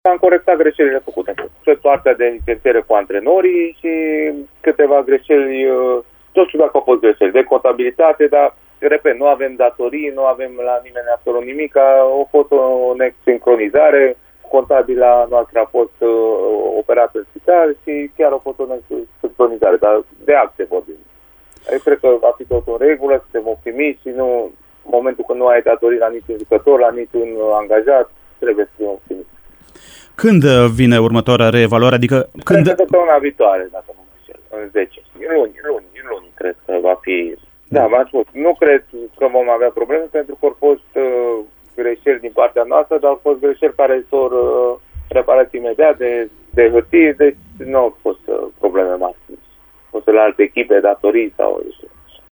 Într-un interviu la Radio Timișoara